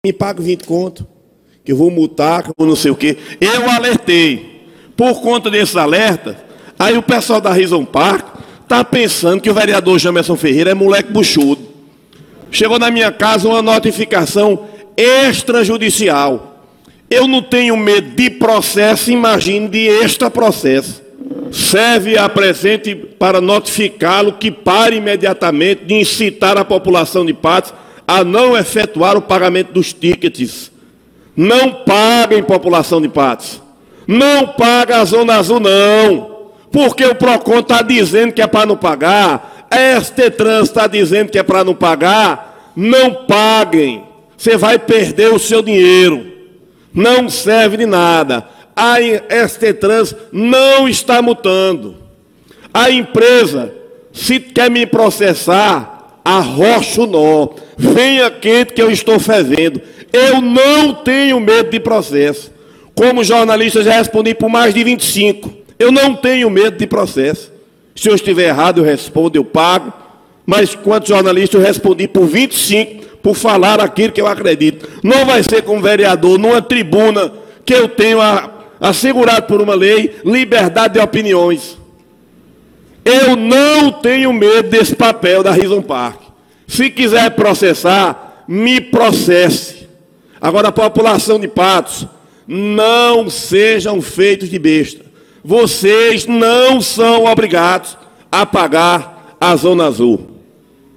O vereador Jamerson Ferreira se pronunciou na noite desta terça-feira (11) durante sessão na Câmara de Vereadores de Patos, sobre uma notificação extra judicial envida a ele pela empresa Rizzo Park – Estacionamento Inteligente.